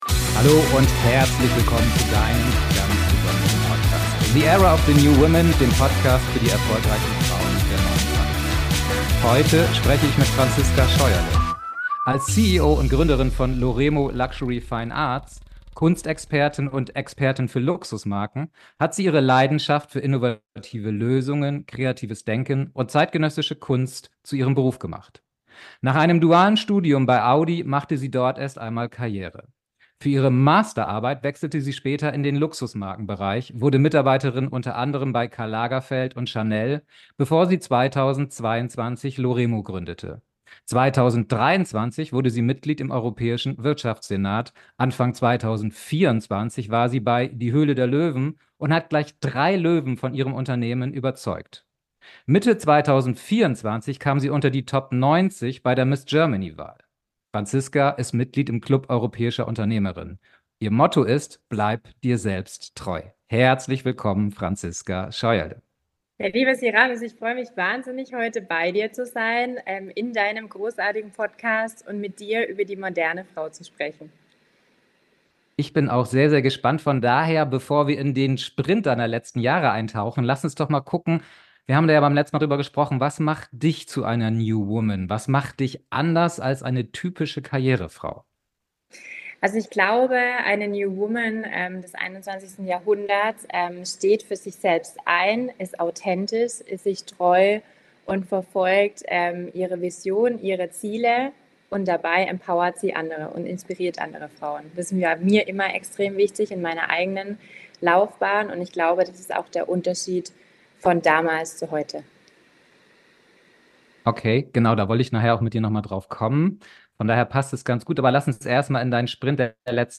#020 Wenn Dein inneres Feuer Dich kaum bändigen kann. Interview